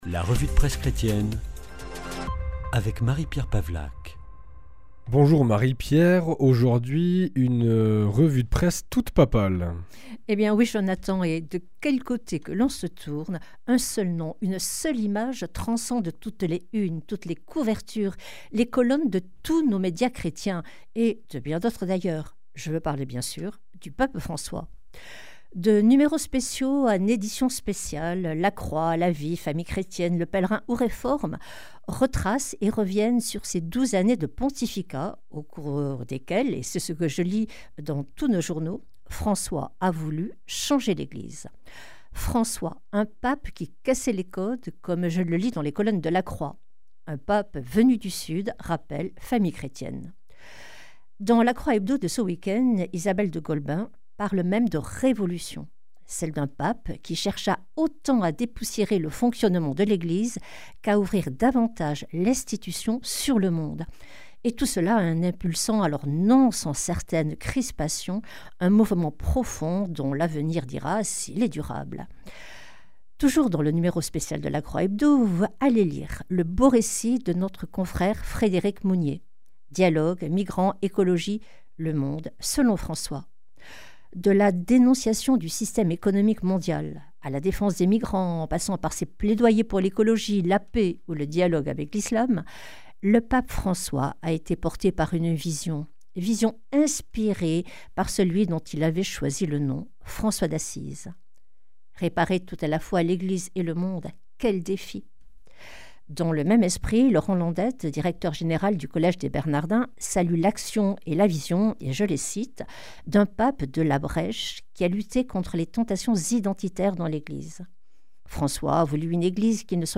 Revue de presse